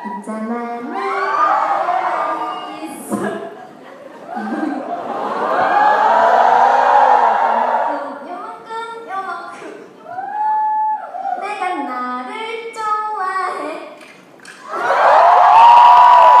告白頌音頻 都在笑XD